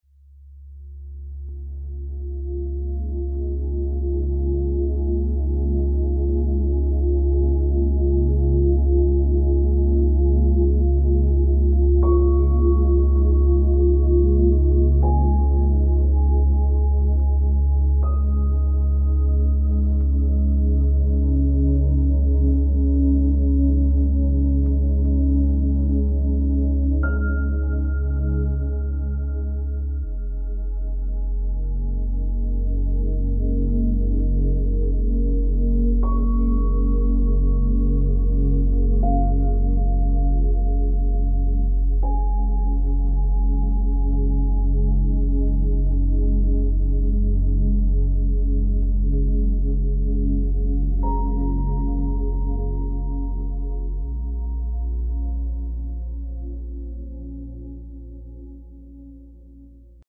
8D Audio.